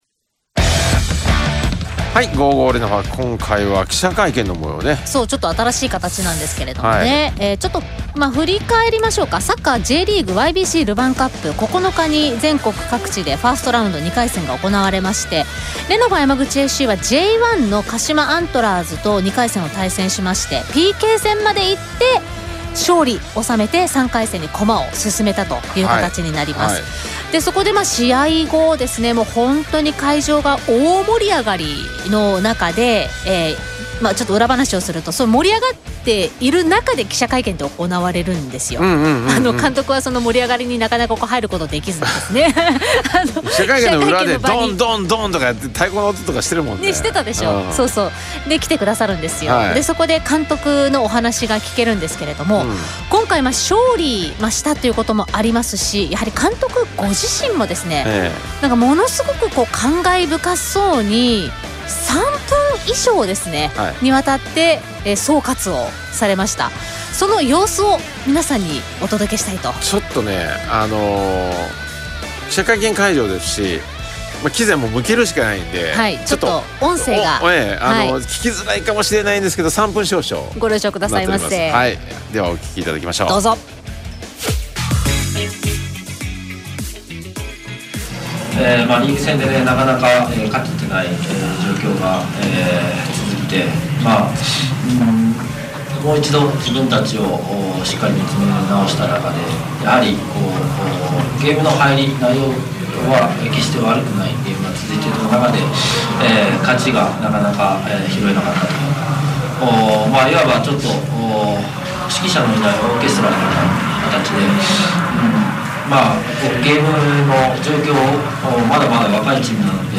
記者会見